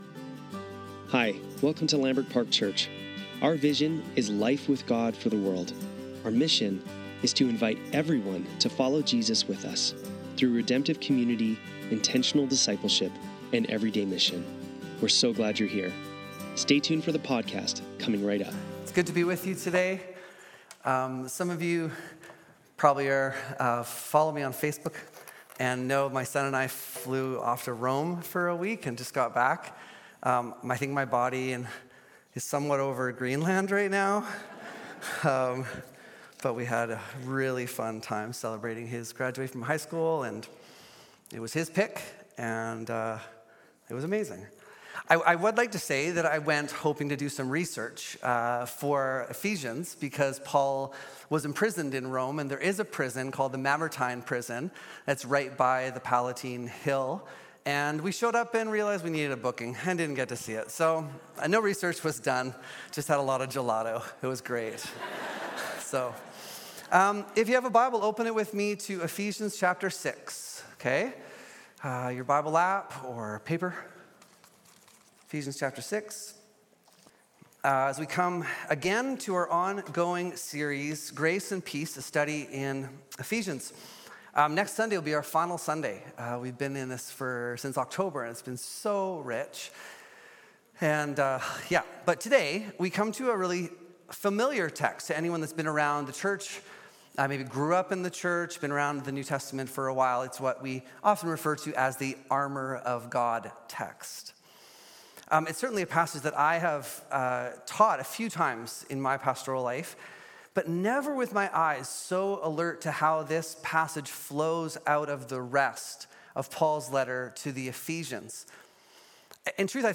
Sunday Service - March 30, 2025